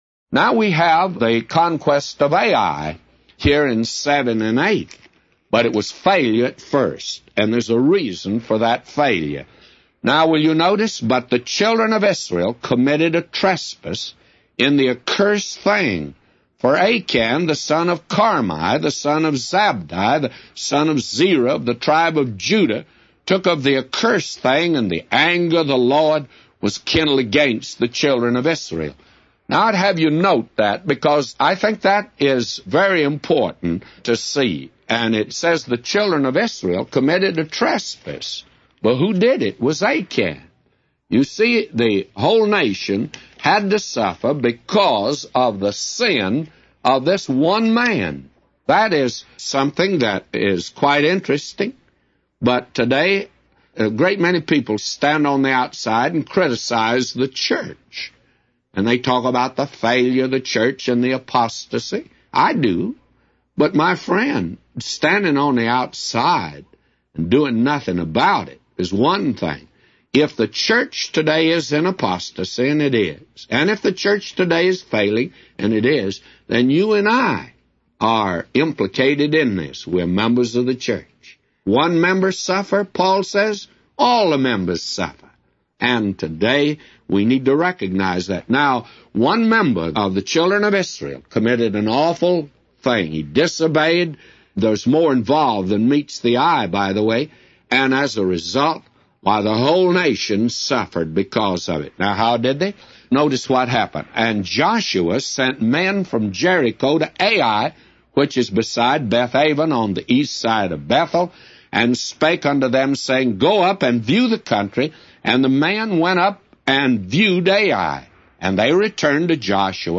A Commentary By J Vernon MCgee For Joshua 7:1-999